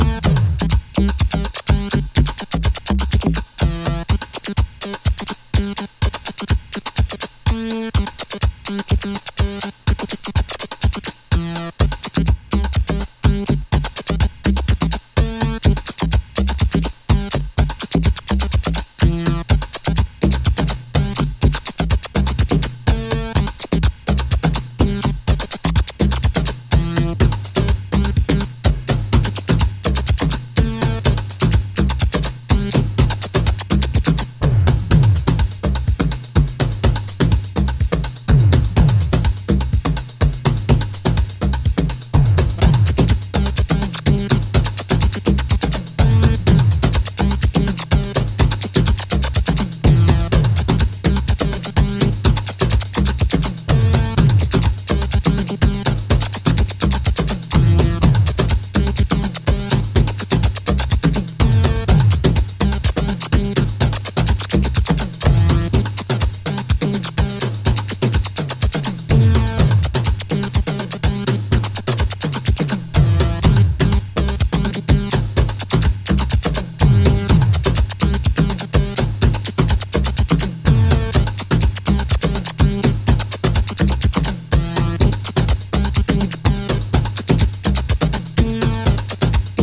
4 raw stripped down Chicago acid cuts